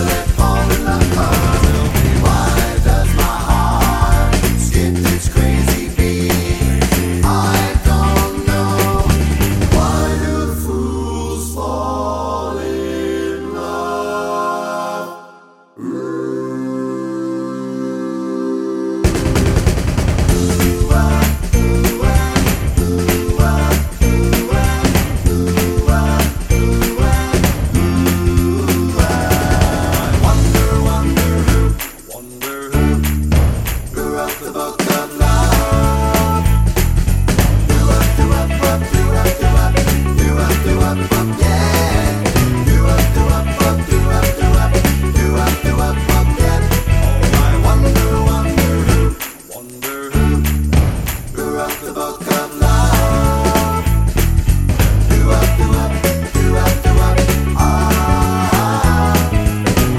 no Backing Vocals Glam Rock 3:01 Buy £1.50